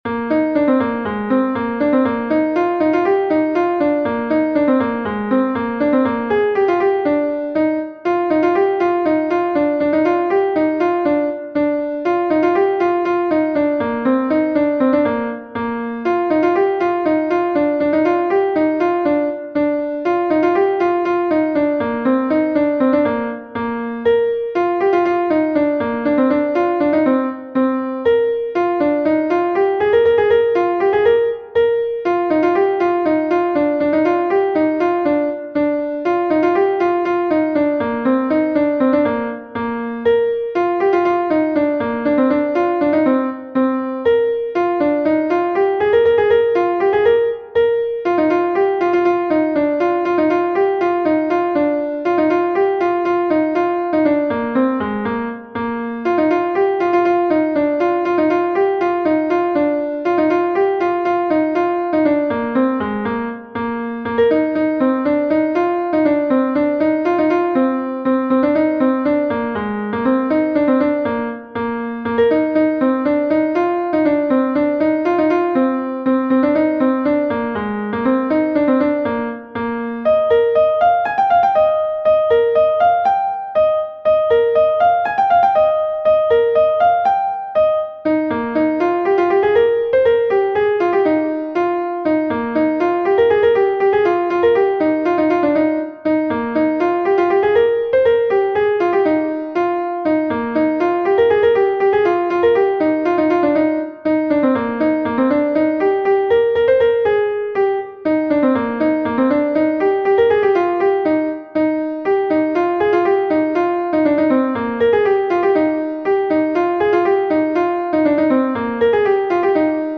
Gavotenn Lokmaloù is a Gavotte from Brittany